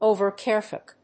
アクセント・音節òver・cáreful